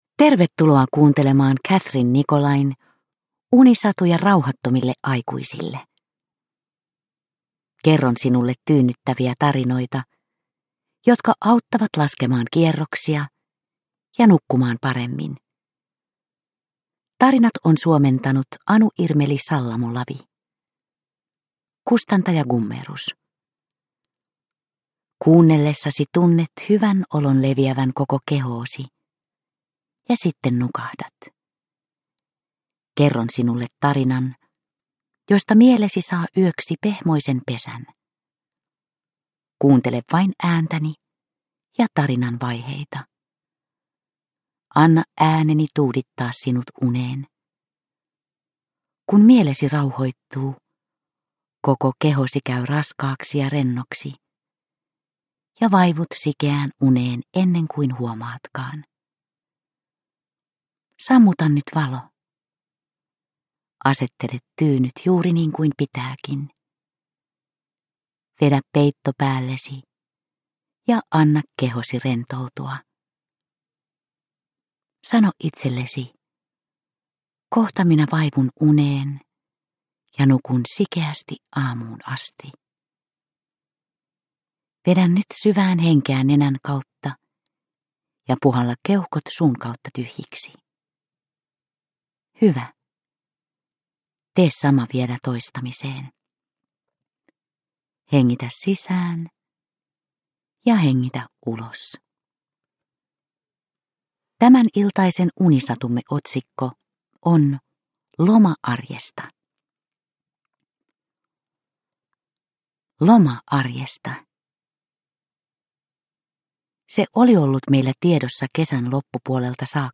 Unisatuja rauhattomille aikuisille 20 - Loma arjesta – Ljudbok – Laddas ner